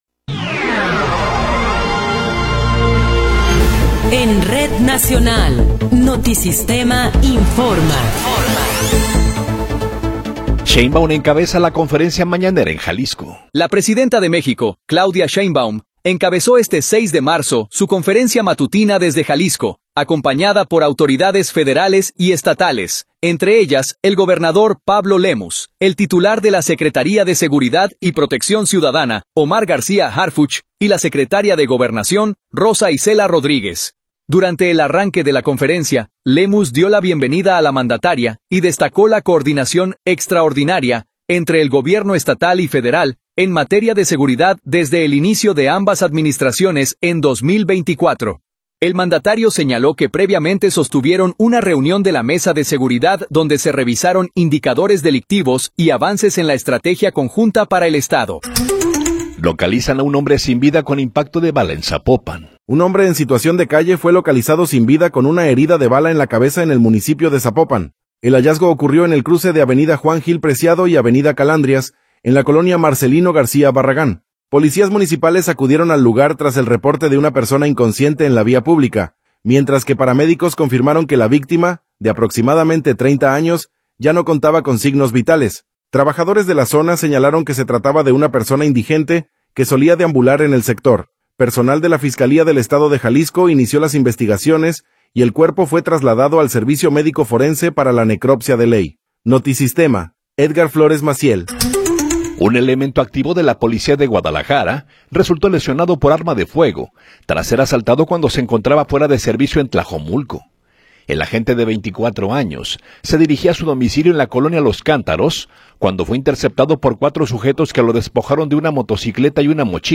Noticiero 10 hrs. – 6 de Marzo de 2026
Resumen informativo Notisistema, la mejor y más completa información cada hora en la hora.